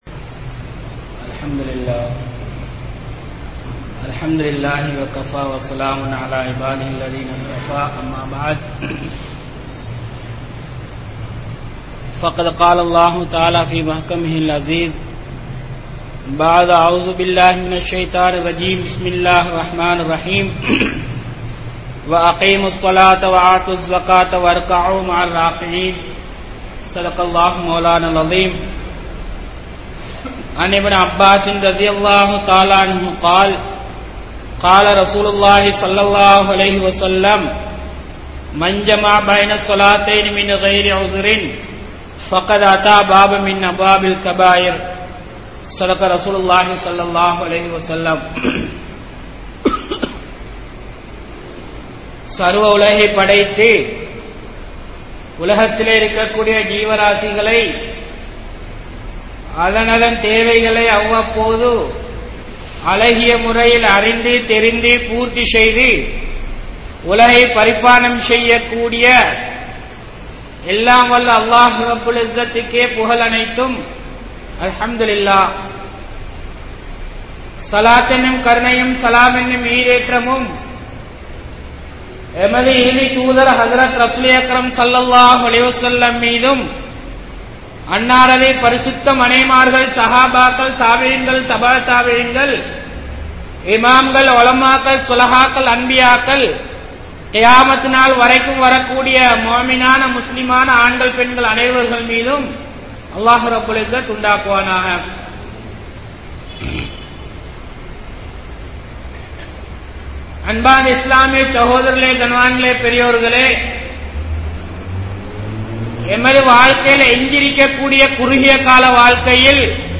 Indraya Samoohathil Tholuhaien Nilai (இன்றைய சமூகத்தில் தொழுகையின் நிலை) | Audio Bayans | All Ceylon Muslim Youth Community | Addalaichenai
Mallawapitiya Jumua Masjidh